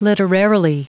Prononciation du mot literarily en anglais (fichier audio)